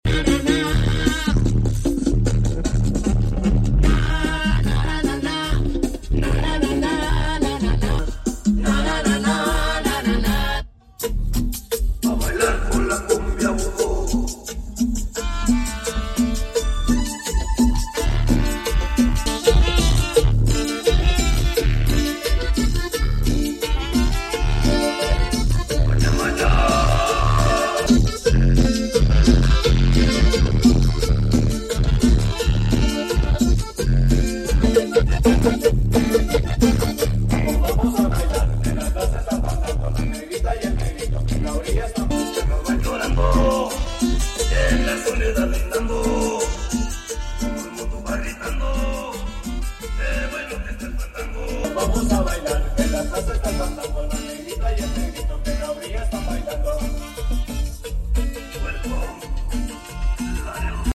en el Aniversario del Corsa Club León